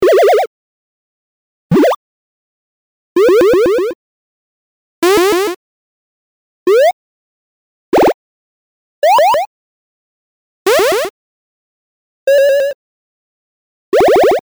8-Bit Powerup Sound Effects (Copyright Free)
Six competely copyright free 8-bit powerup sound effects.
8-bit-powerup-sound-effects.mp3